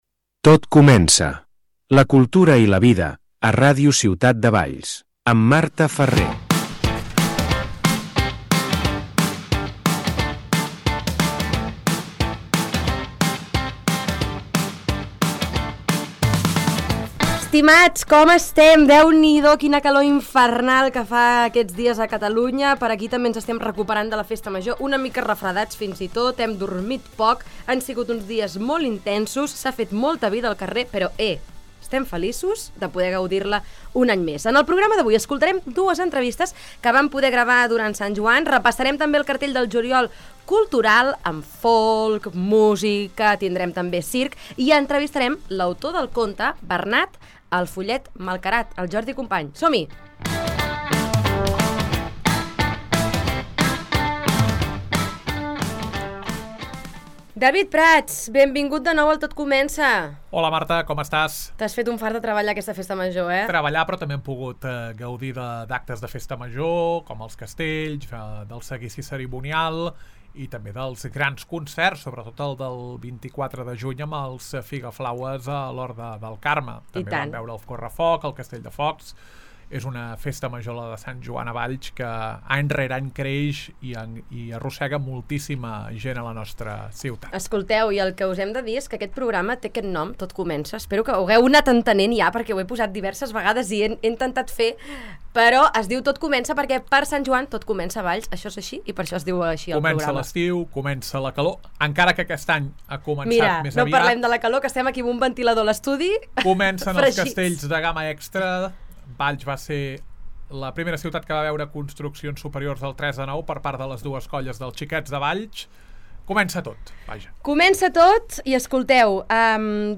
Hem pogut parlar amb els Figa Flawas i Manu Guix, que va haver de suspendre el concert a Valls a causa de la pluja.